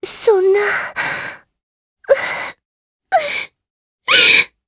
Erze-Vox_Sad_jp.wav